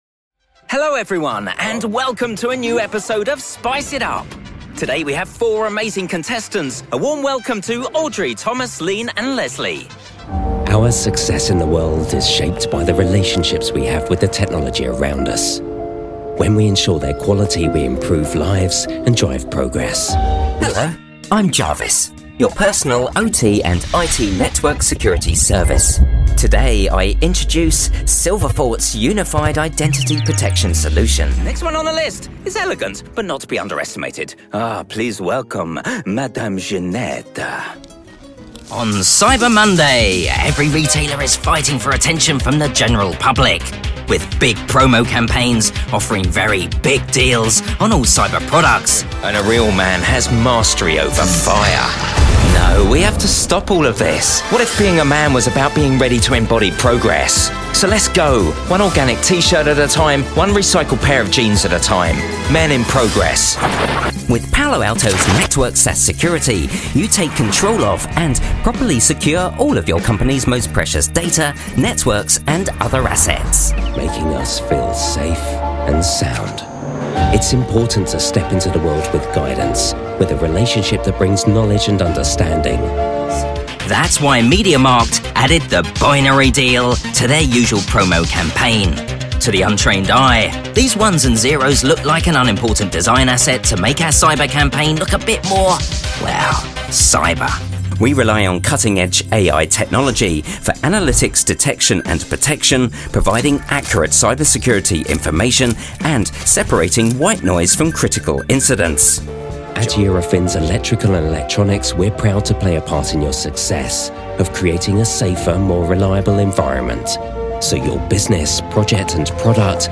English (British) voice actor